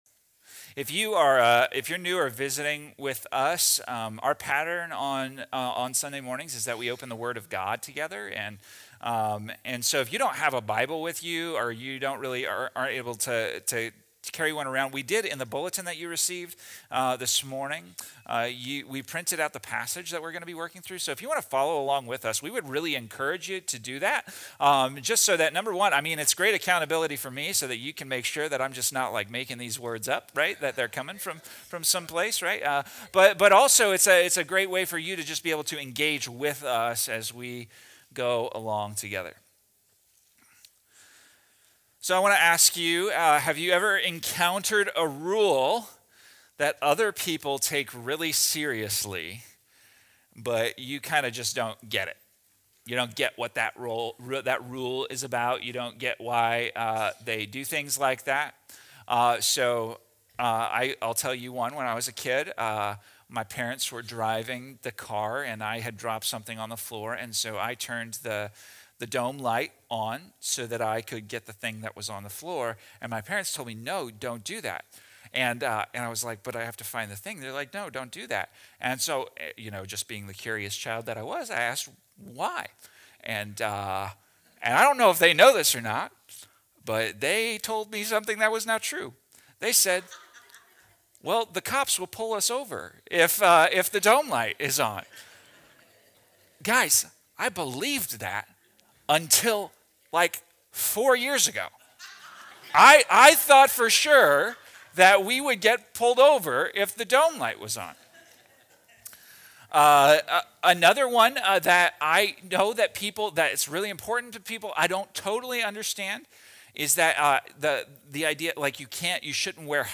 The sermon warns against resisting God’s Spirit, points to Jesus as our true Sabbath rest, and urges us to trust Him fully rather than reject His grace.